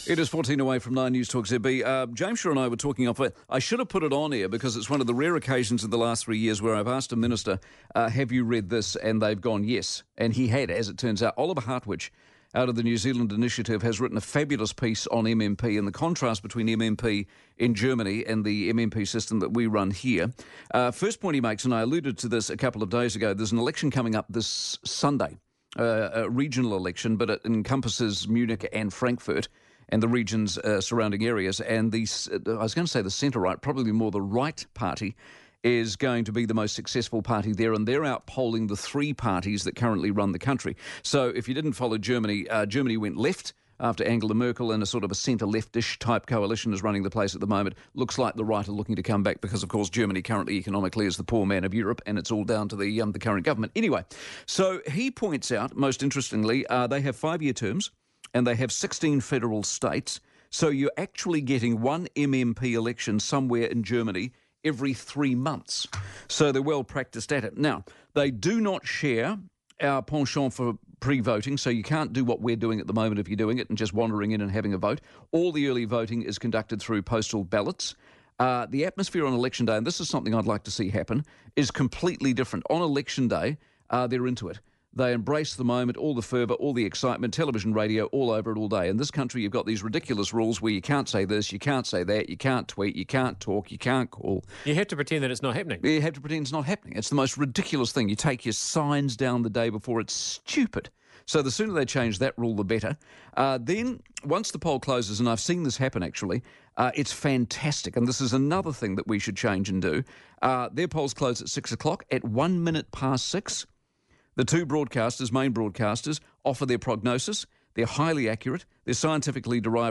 Mike Hosking comments on this column, NewstalkZB, 5 October 2023